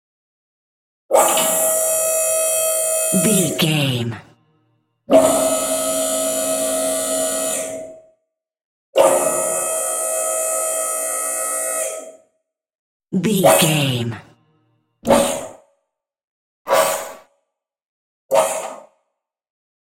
Hydraulic motor
Sound Effects